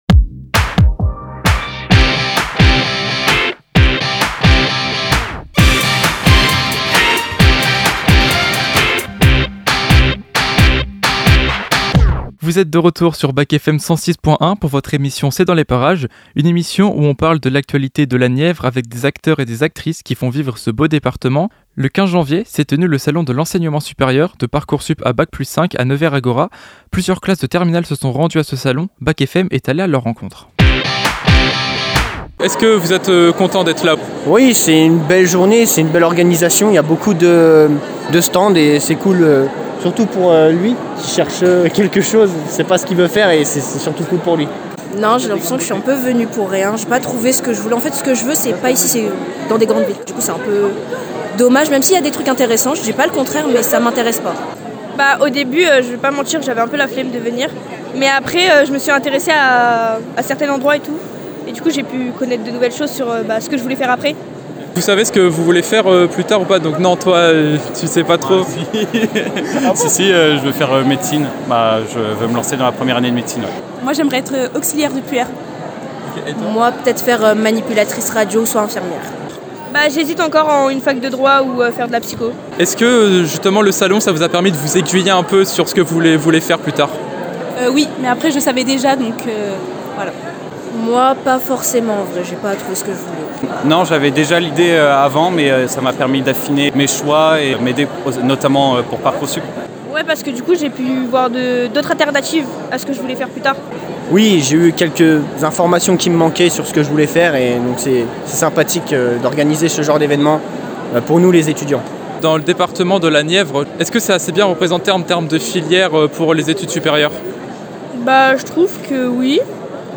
C'est dans les parages - Reportage au Salon de l'enseignement supérieur en podcast - BAC FM